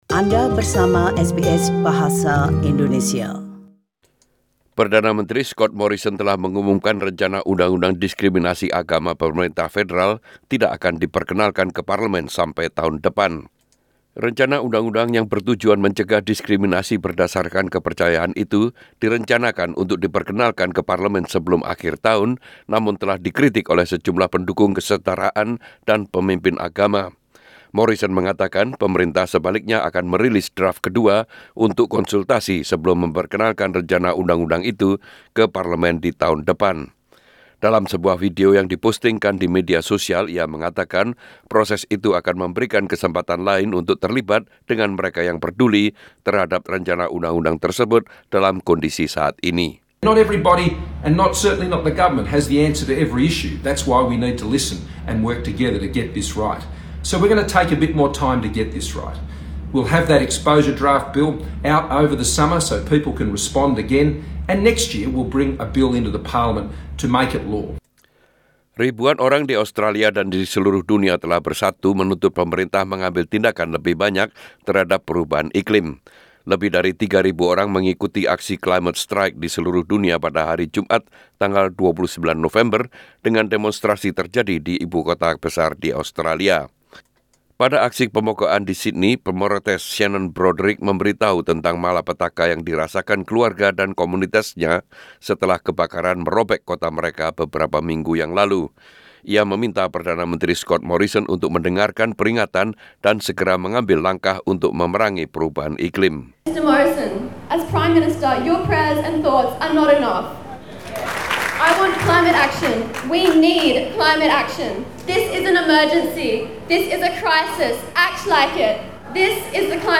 SBS Radio News in Indonesia - 01 December 2019